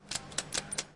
插曲：门把手扭动释放
描述：门把手扭曲然后松开。
标签： 清洁 sounddesign 门把手 gamesound SFX schoeps 总之 拟音 旋钮
声道立体声